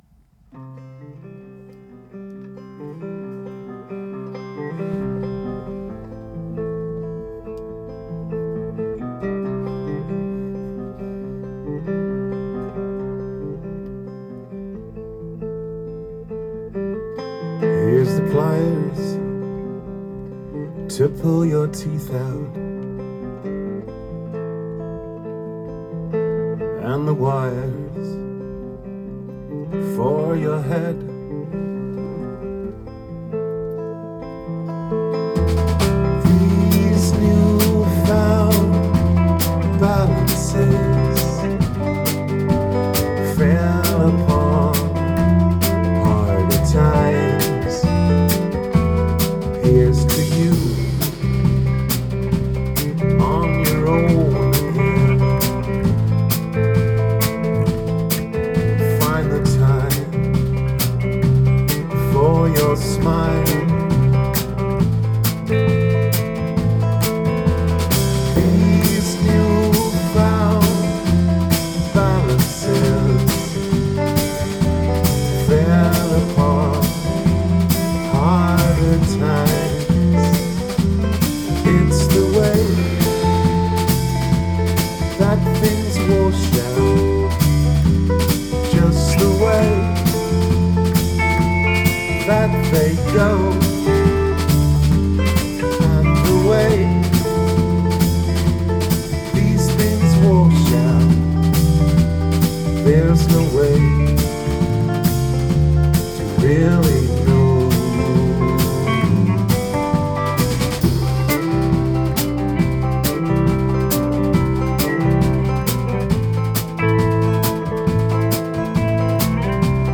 Rehearsals 16.8.2013